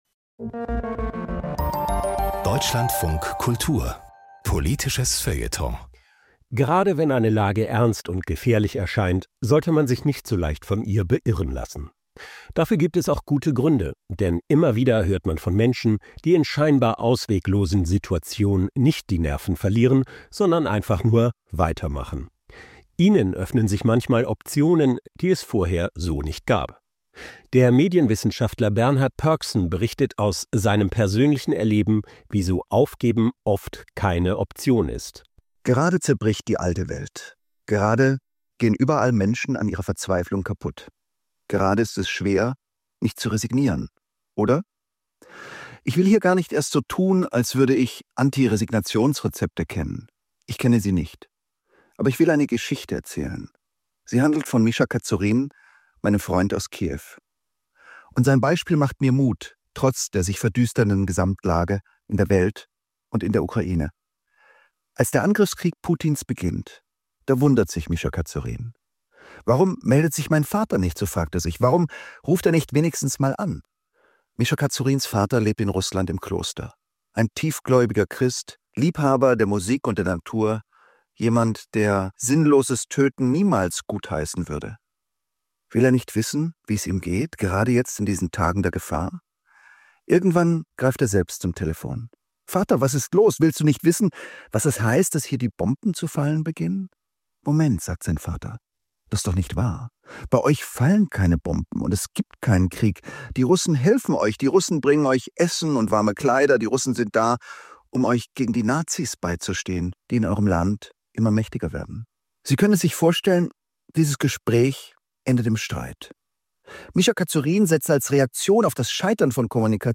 Kommentar von Bernhard Pörksen - Der Luxus der Resignation